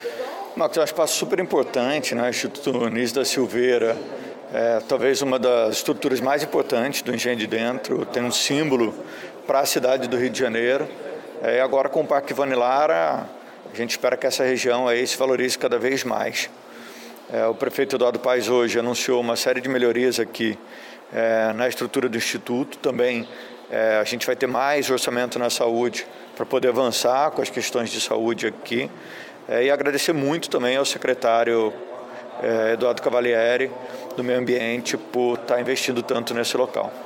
O secretário de Saúde, Daniel Soranz falou da importância do espaço para a região.